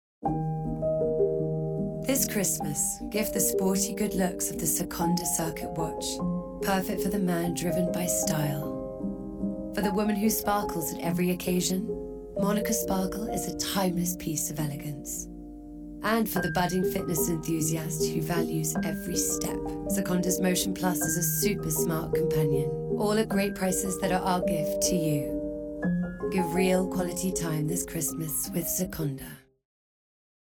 RP
Female
Cool
Fresh
Husky
SEKONDA COMMERCIAL